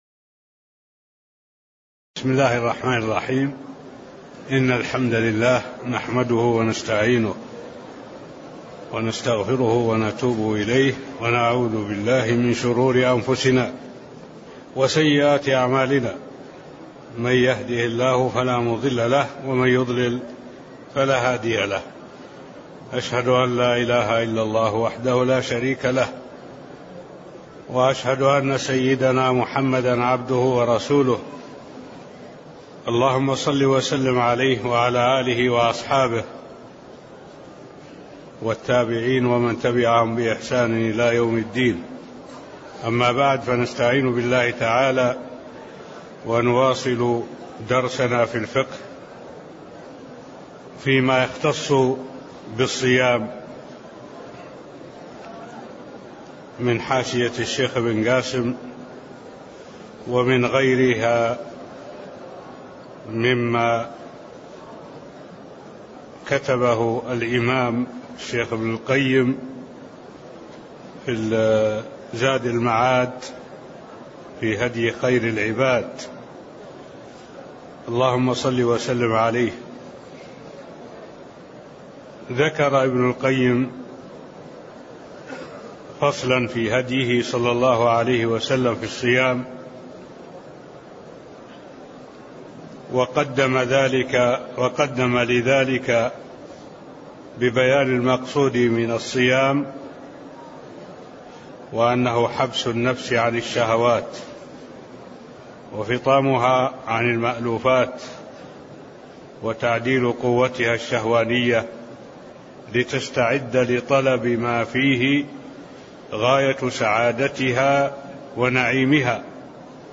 المكان: المسجد النبوي الشيخ: معالي الشيخ الدكتور صالح بن عبد الله العبود معالي الشيخ الدكتور صالح بن عبد الله العبود كتاب الصيام من قوله: (فصل في هديه صلى الله عليه وسلم في الصوم) (01)